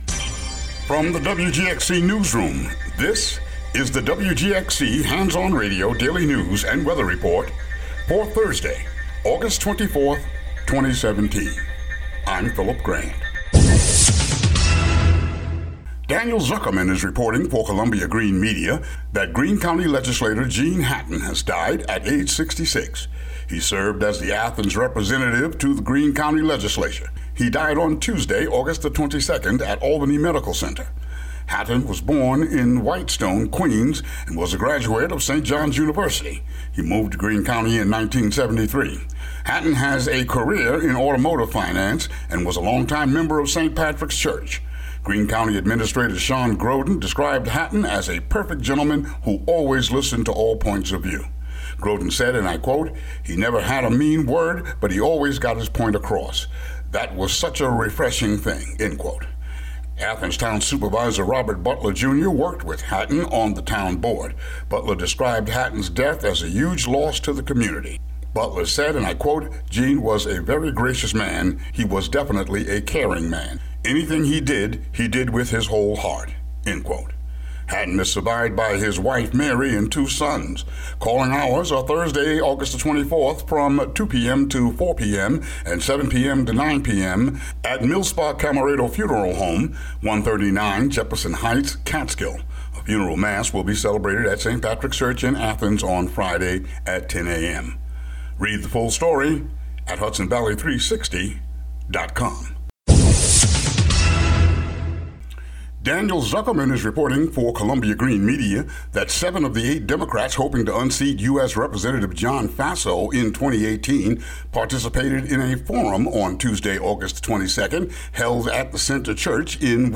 WGXC daily headlines for August 24, 2017.